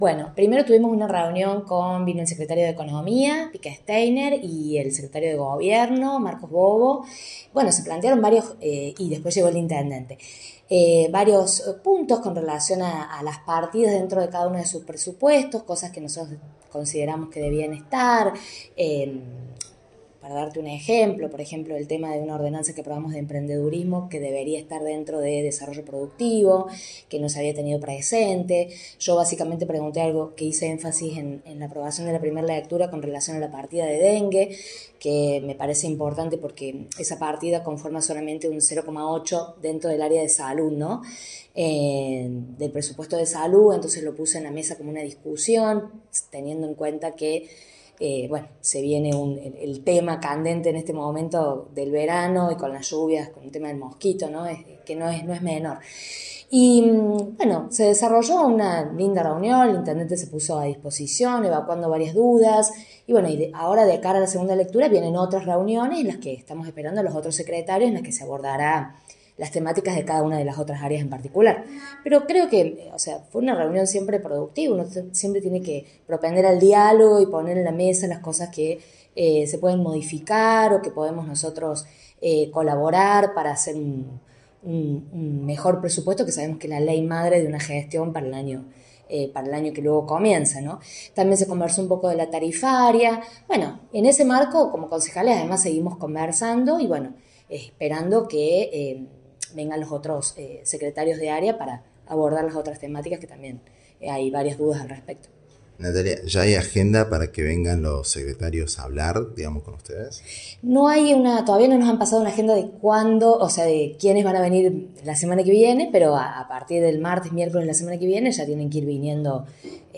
EleccionarioVM dialogó con Natalia González, edil opositora, a quien se le consultó sobre los temas que debatieron durante las horas de reunión.